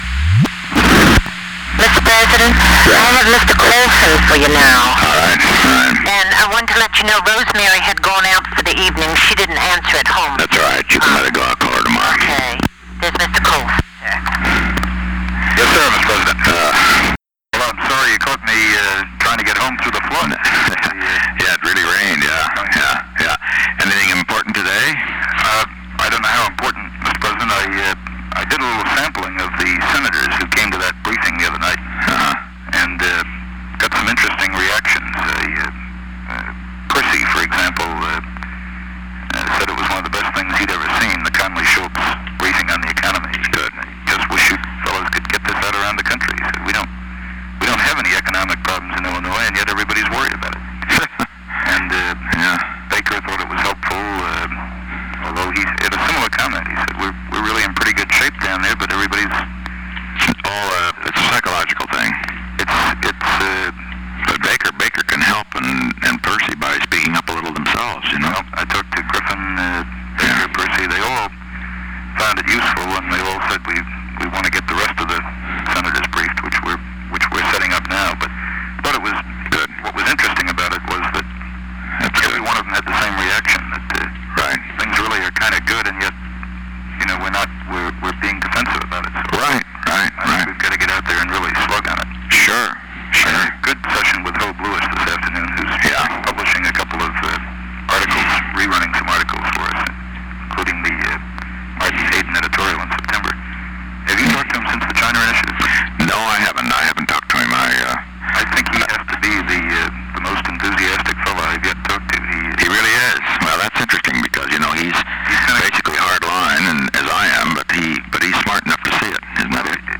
On July 29, 1971, President Richard M. Nixon, White House operator, and Charles W. Colson talked on the telephone from 8:00 pm to 8:19 pm. The White House Telephone taping system captured this recording, which is known as Conversation 007-032 of the White House Tapes.